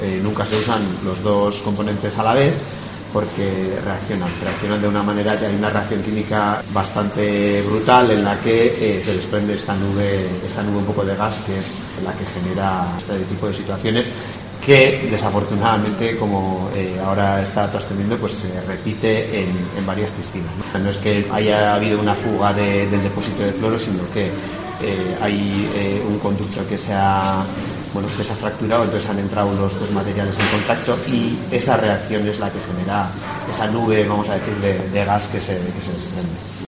Adur Ezenarro, alcalde de Ordizia